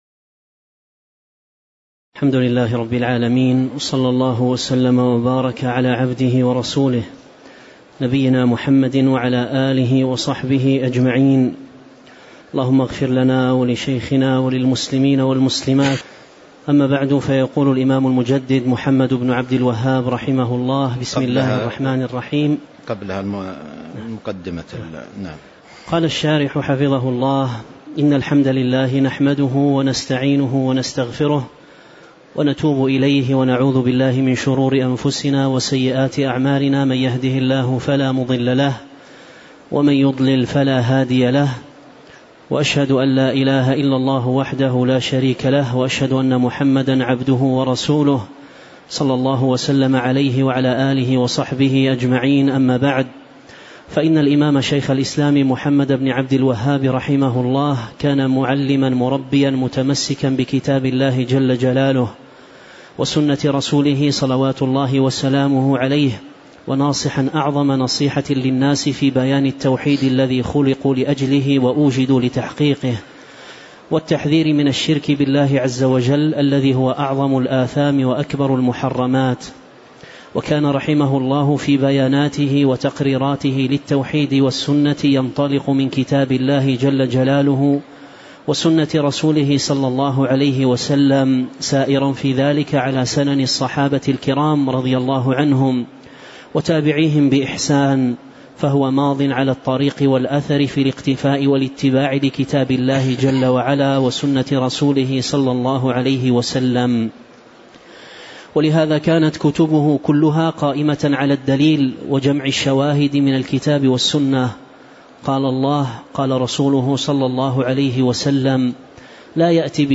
تاريخ النشر ٢٤ شوال ١٤٤٤ هـ المكان: المسجد النبوي الشيخ